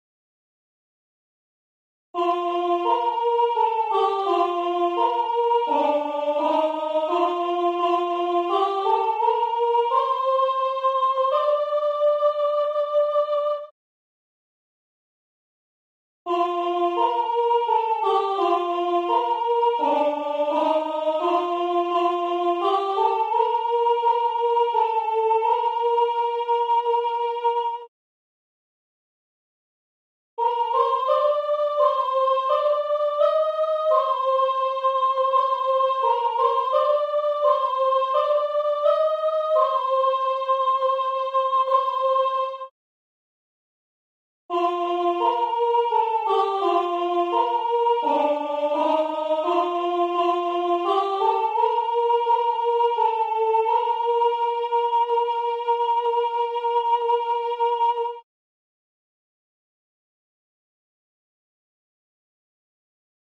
SOPRANI